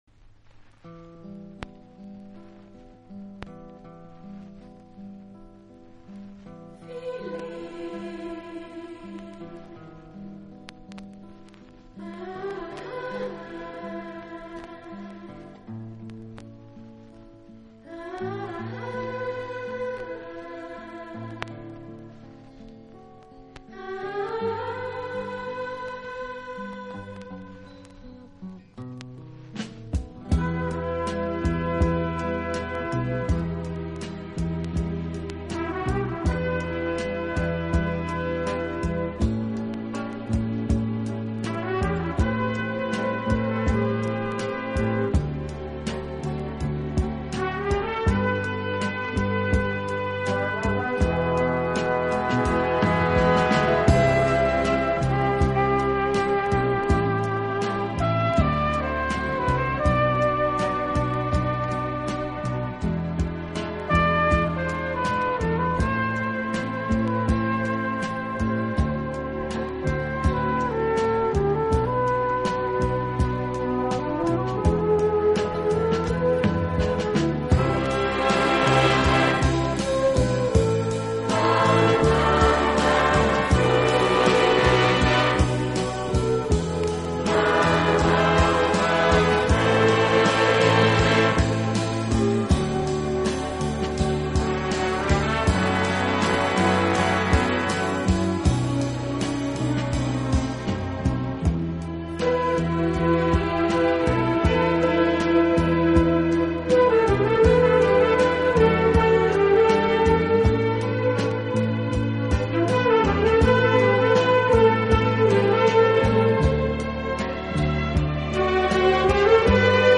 【轻音乐】
温情、柔软、浪漫是他的特色，也是他与德国众艺术家不同的地方。